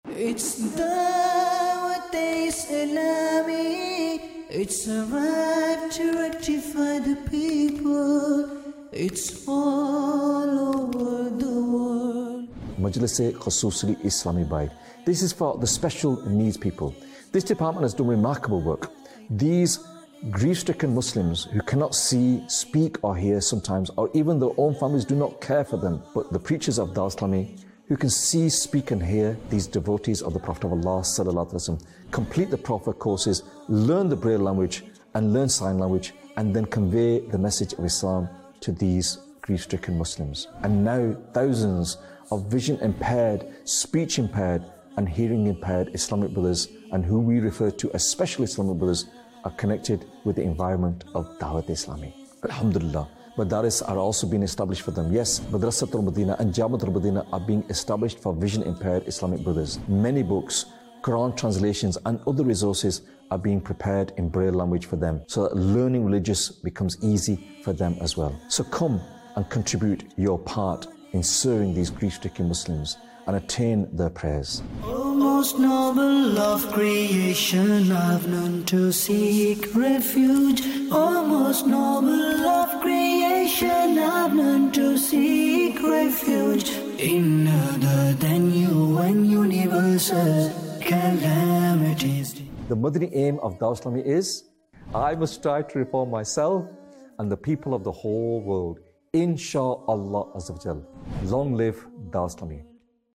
Special Persons Department | Department of Dawateislami | Documentary 2025